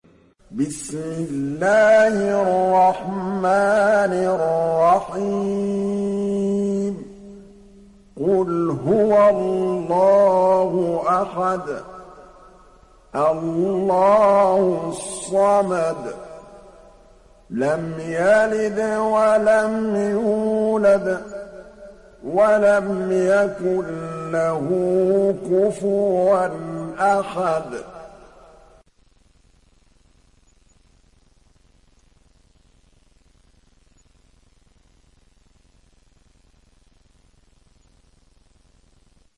Surat Al Ikhlas mp3 Download Muhammad Mahmood Al Tablawi (Riwayat Hafs)